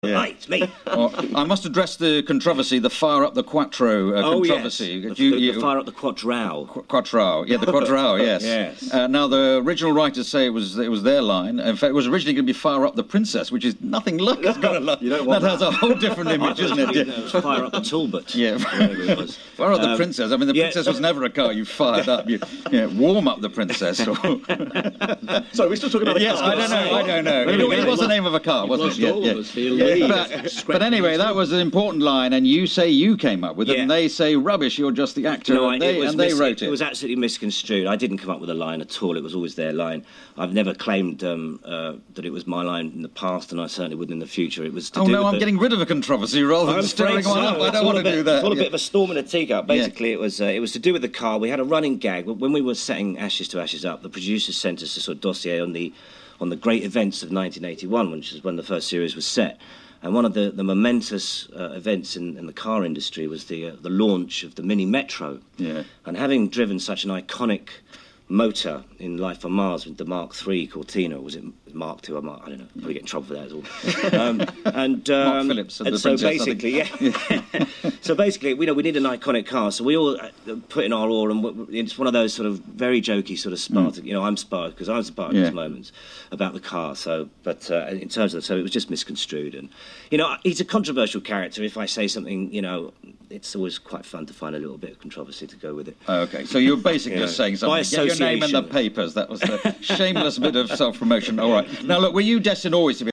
Philip Glenister gave his side of the “Quattrow” story on BBC Radio 4’s Loose Ends programme tonight.
Here’s the audio of him being asked about it by Loose Ends presenter Clive Anderson:
It’s a shame that jovial host Clive then interrupted and said: